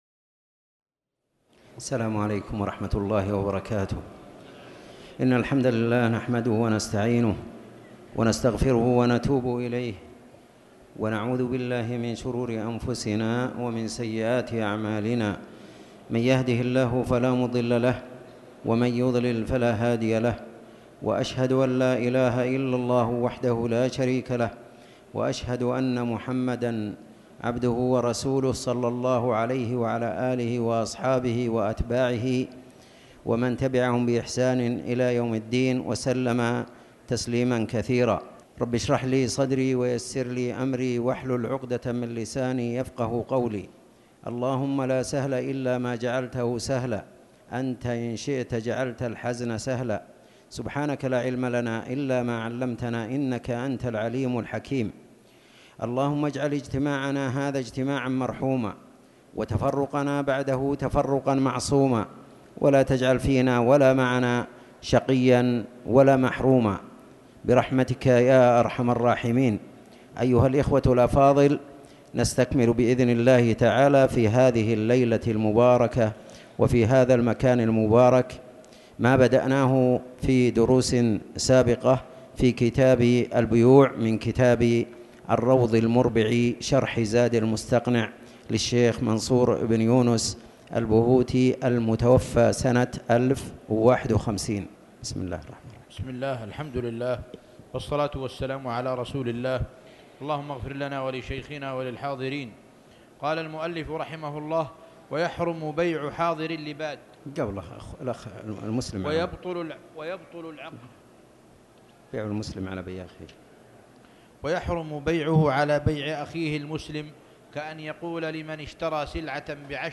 تاريخ النشر ١١ جمادى الآخرة ١٤٣٩ هـ المكان: المسجد الحرام الشيخ